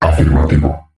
• Samples de  Voz